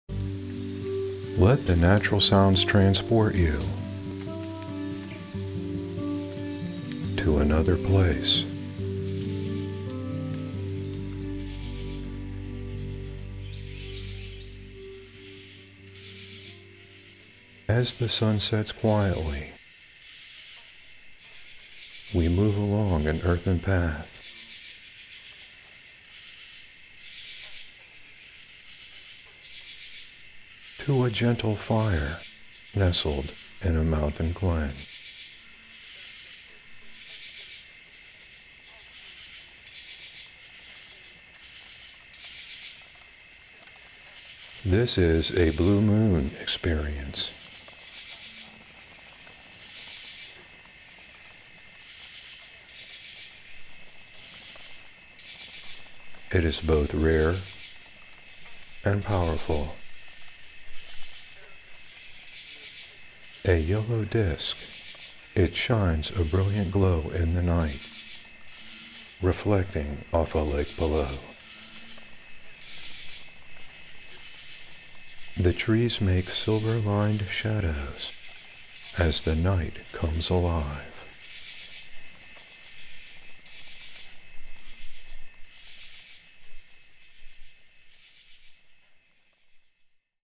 Zvuky přírody vás zanesou na místo nestárnoucí moudrosti.